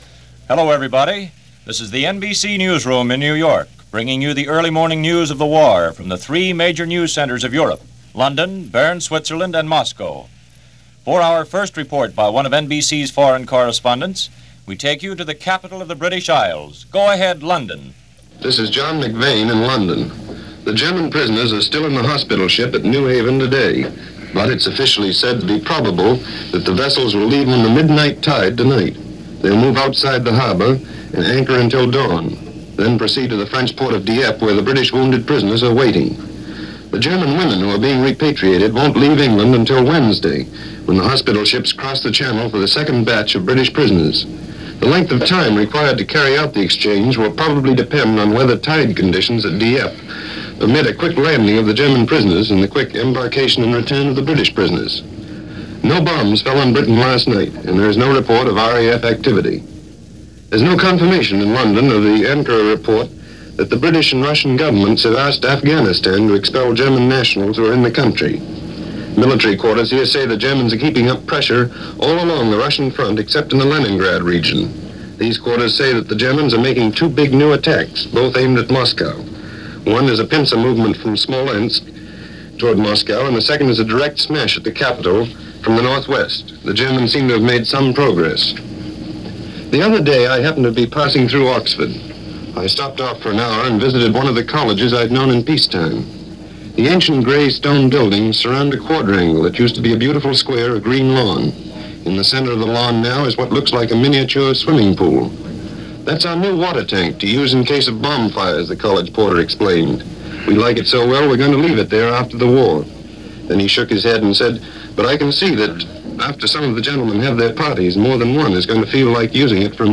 News for this day in 1941 as presented by NBC Radio Morning news